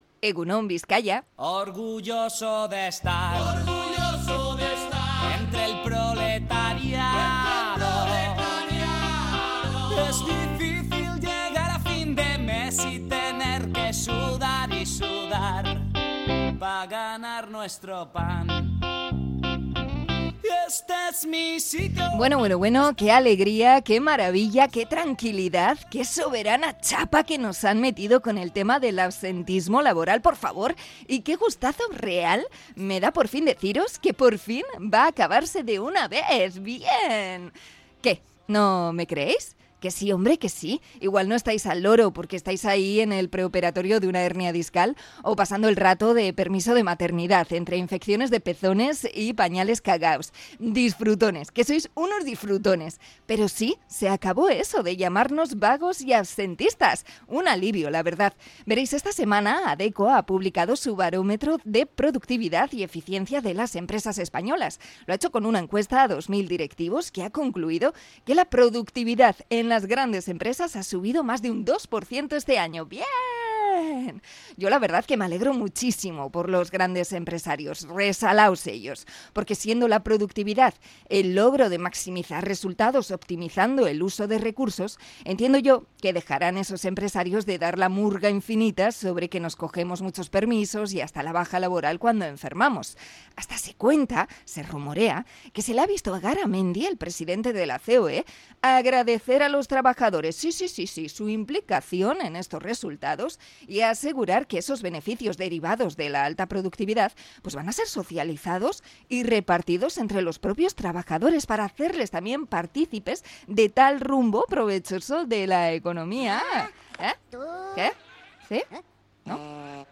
Comentario sobre la productividad laboral y el mito del absentismo